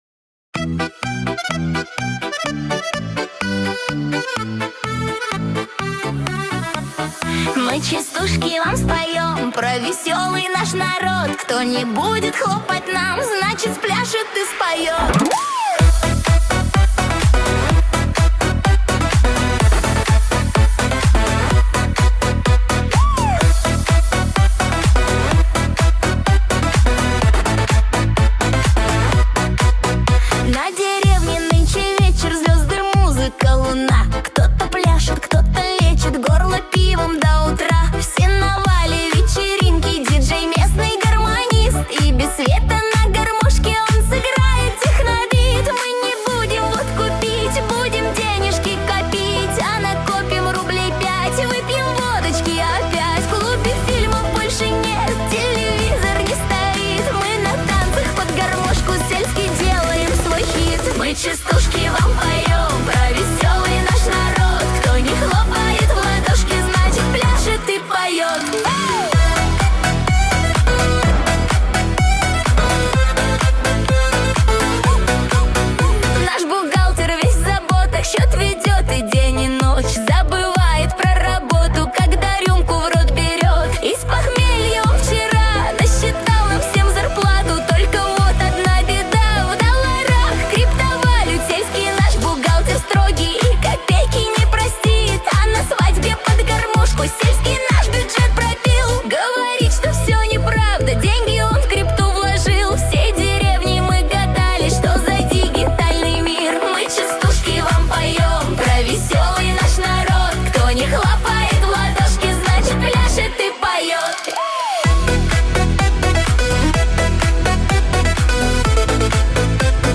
Набор шуточных частушек о жизни в деревне.